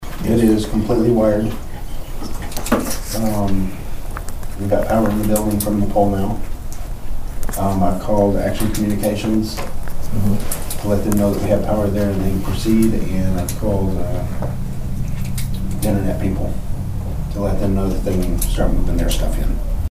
The Nowata County Commissioners met for a regularly scheduled meeting on Monday morning at the Nowata County Annex.
Chairman Paul Crupper gave an update on the radio tower maintenance in New Alluwe.